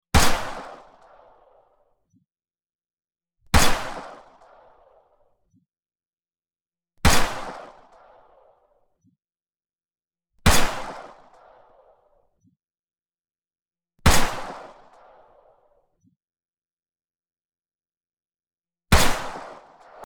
Gunshot Pistol
Gunshot_pistol.mp3